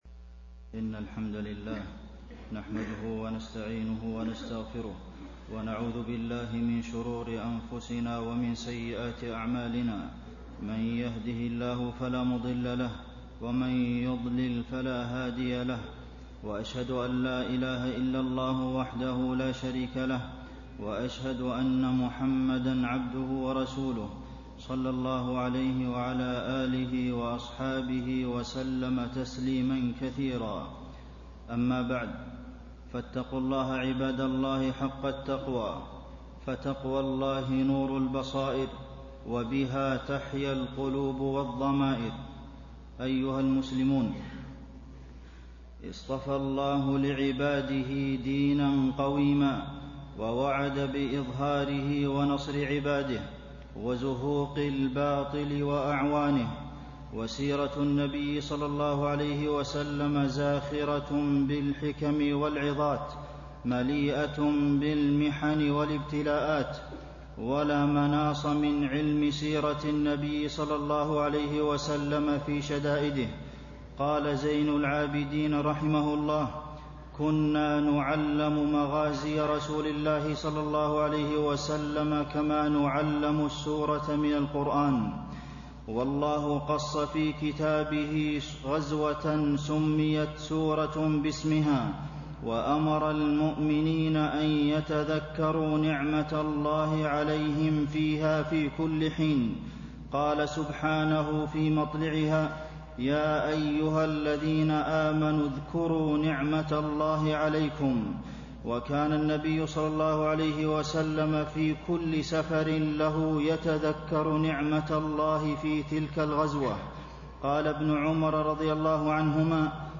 تاريخ النشر ١٤ رجب ١٤٣٤ هـ المكان: المسجد النبوي الشيخ: فضيلة الشيخ د. عبدالمحسن بن محمد القاسم فضيلة الشيخ د. عبدالمحسن بن محمد القاسم غزوة الأحزاب دروس وعبر